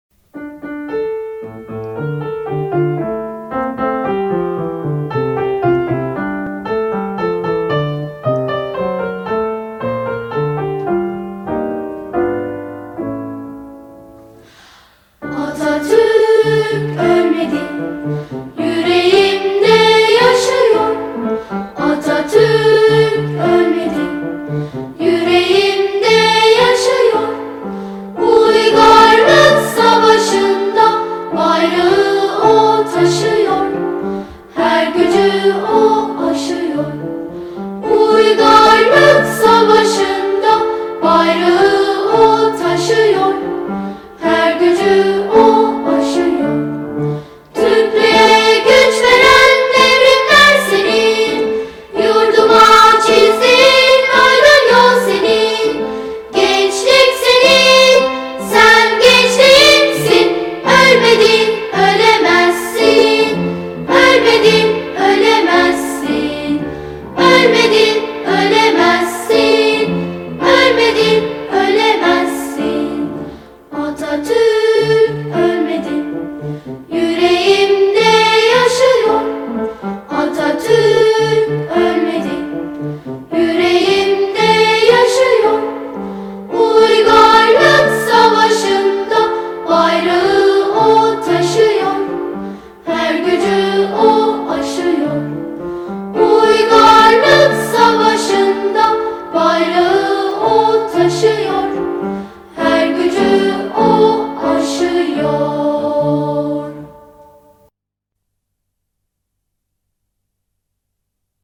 10 Kasım Atatürk’ü Anma Günü ve Atatürk Haftası Etkinlikleri Koro Bireysel Çalışma Kayıtları
Ataturk-Olmediiii-Cocuk-SarkilariMP3_320K.mp3